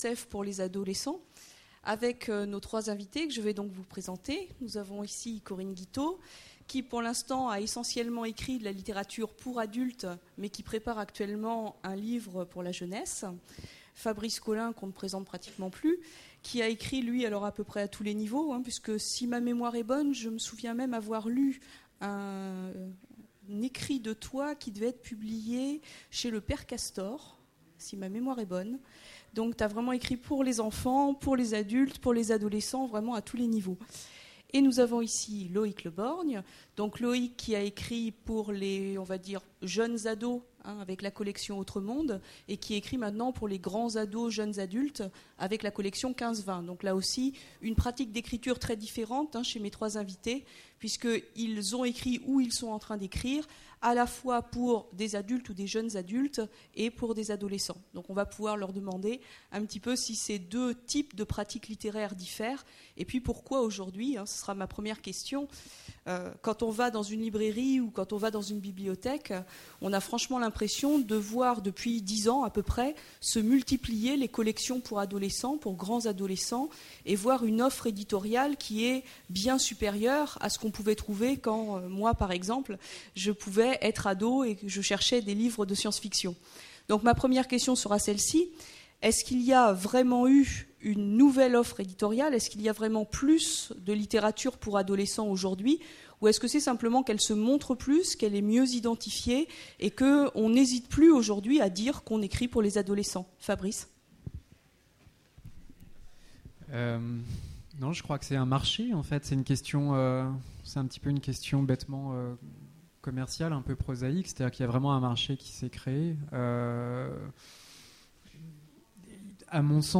Voici l'enregistrement de la conférence sur la littérature jeunesse aux Utopiales 2008.